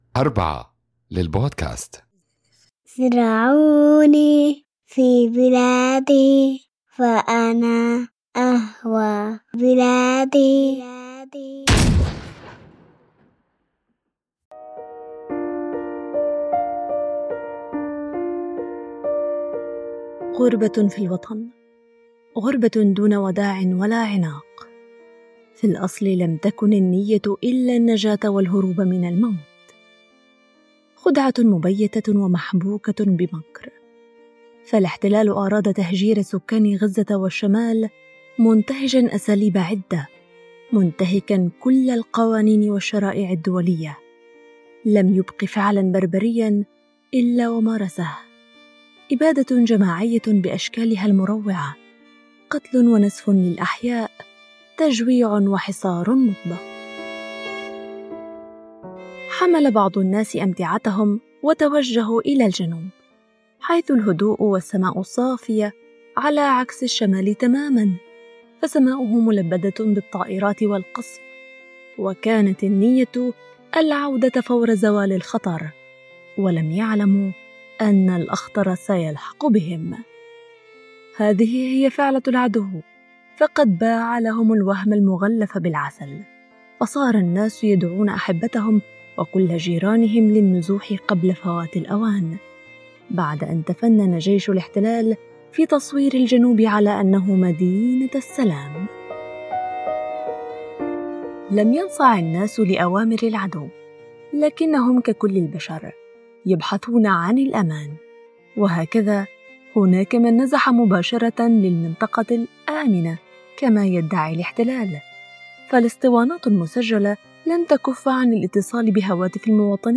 نستمع إلى شهادات مؤثرة لأمهات وأطفال واجهوا قرارات صعبة تحت وطأة الحرب، ونرى كيف تحولت المدارس إلى ملاجئ، وكيف تشتت العائلات بين الشمال والجنوب.